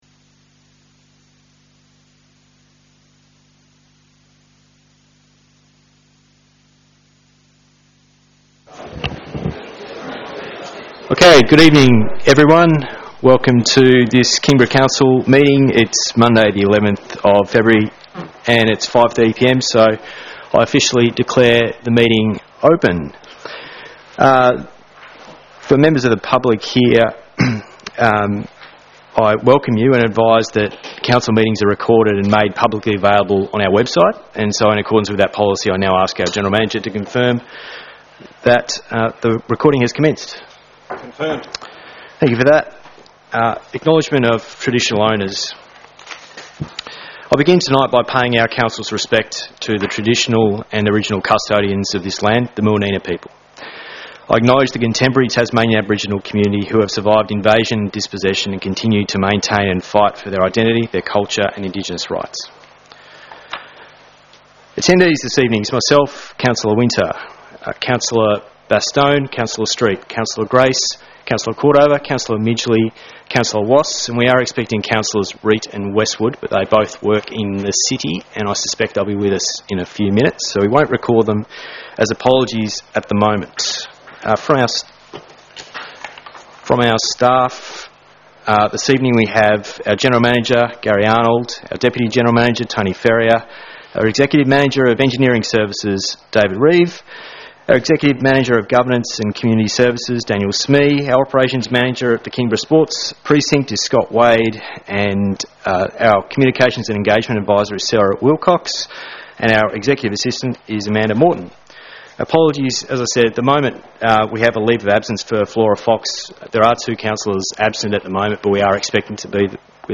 Council Meeting, 11 February 2020